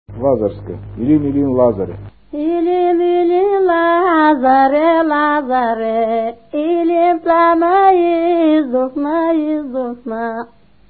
музикална класификация Песен
размер Две четвърти
фактура Едногласна
начин на изпълнение Солово изпълнение на песен
функционална класификация Ритуални (календарни обичаи)
фолклорна област Североизточна България
начин на записване Магнетофонна лента